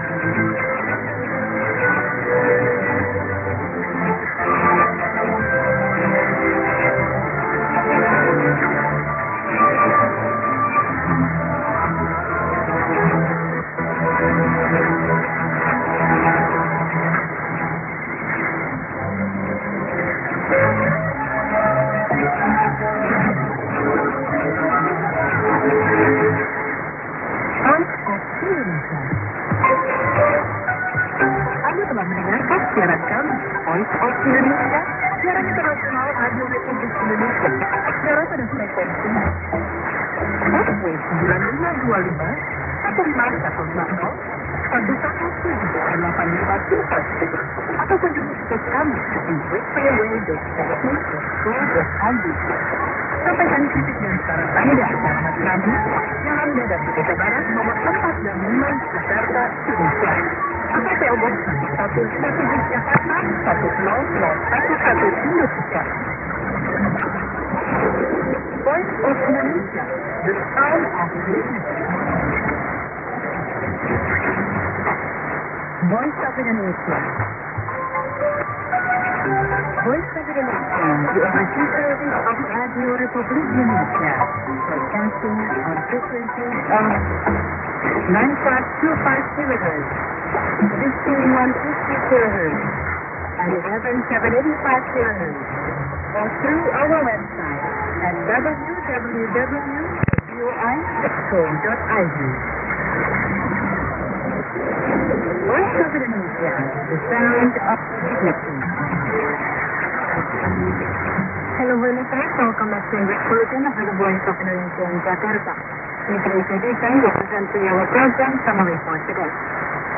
・このＨＰに載ってい音声(ＩＳとＩＤ等)は、当家(POST No. 488-xxxx)愛知県尾張旭市で受信した物です。
ID: identification announcement